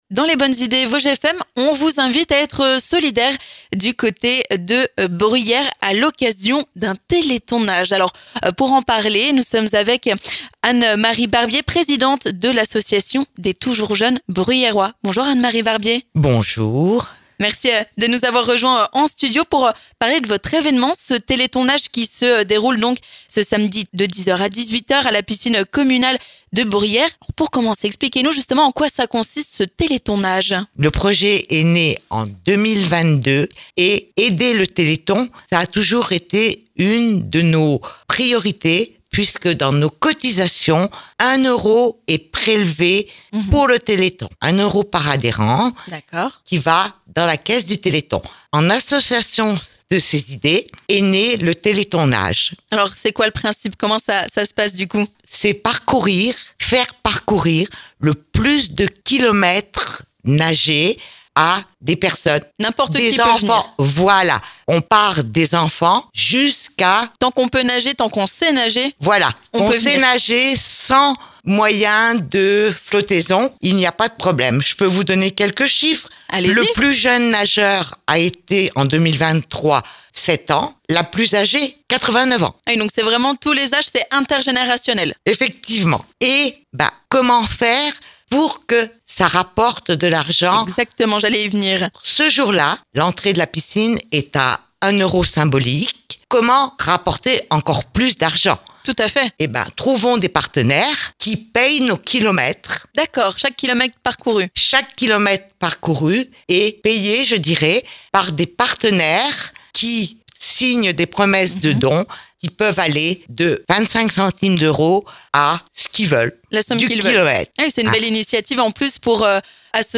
Pour en savoir, nous accueillons en studio